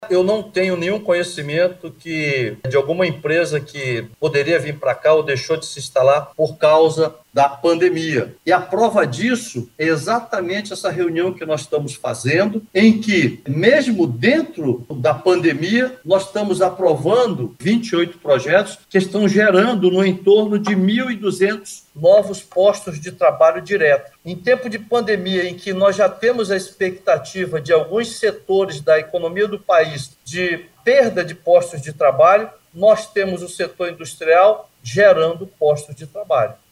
Perguntado se alguma empresa desistiu de se instalar em Manaus por causa da pandemia, o superintendente da Suframa, Alfredo Menezes afirmou que não.